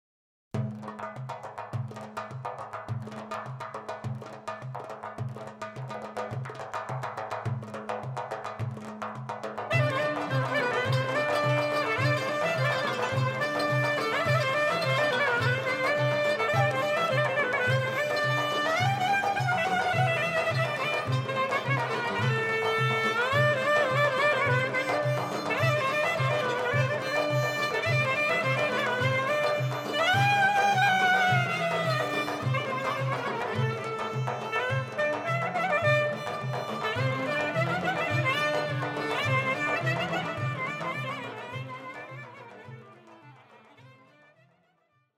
Genres: Turkish Traditional, Roman.
clarinet
violin
kanun
darbuka
Recorded on November 3rd, 1999 in Istanbul at Audeon Studios